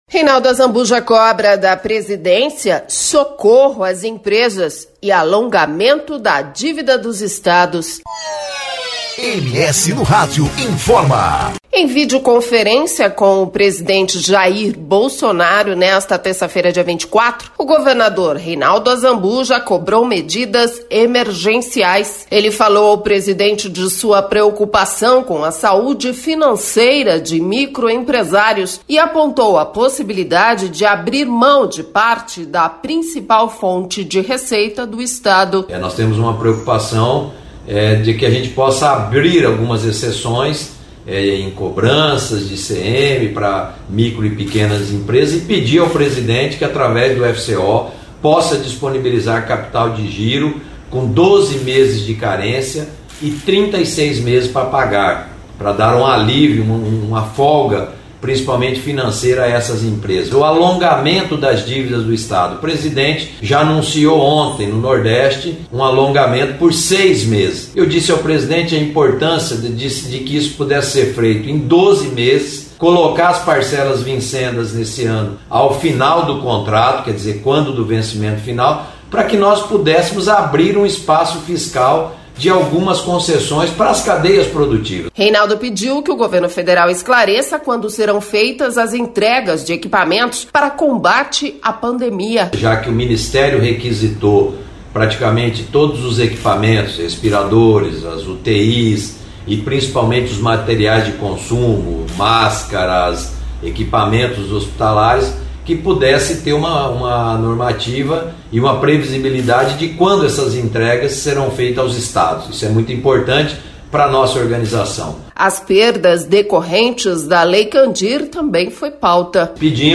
24.03-BOLETIM-AUDIENCIA-BOLSONARO-PORTAL.mp3